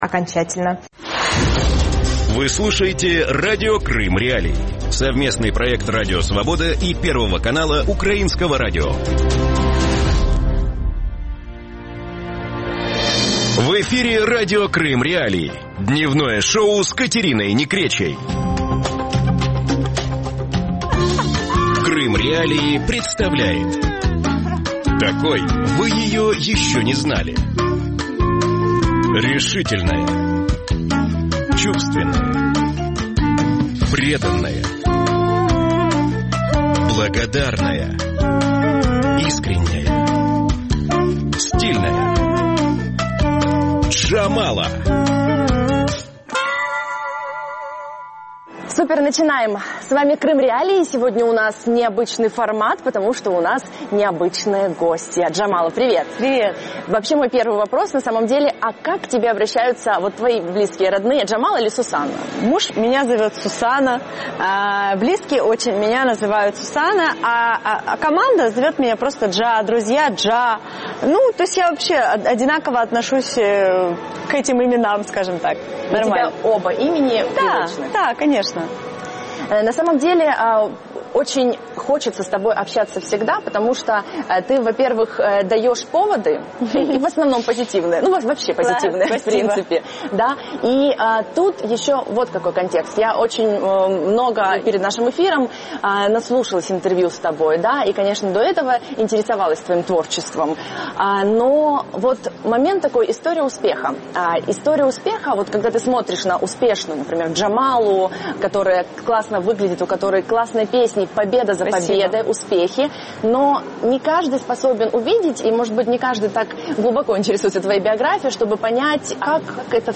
Эксклюзивное интервью Крым.Реалии с украинской певицей Джамалой! Как у Джамалы продвигается работа над новым альбомом?
Гость – украинская певица Jamala | Джамала.